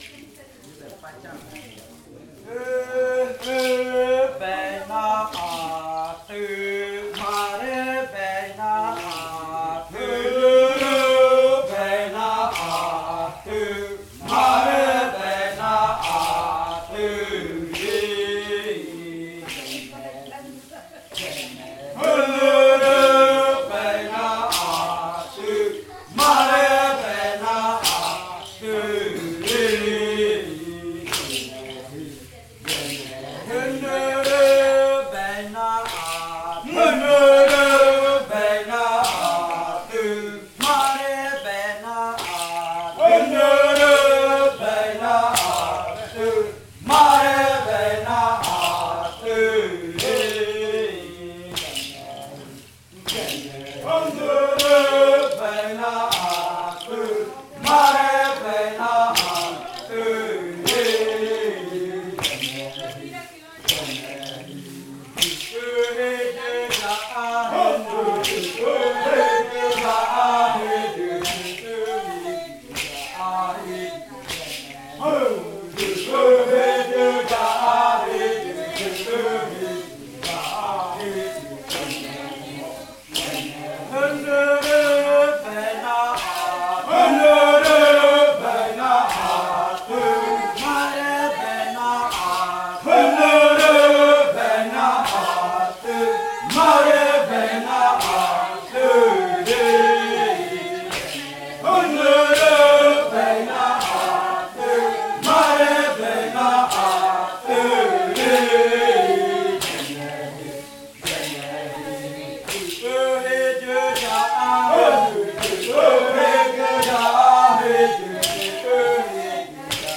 Canto de la variante muruikɨ
Leticia, Amazonas
con el grupo de cantores bailando en Nokaido. Este canto hace parte de la colección de cantos del ritual yuakɨ murui-muina (ritual de frutas) del pueblo murui, colección que fue hecha por el Grupo de Danza Kaɨ Komuiya Uai con el apoyo de un proyecto de extensión solidaria de la UNAL, sede Amazonia.
with the group of singers dancing in Nokaido.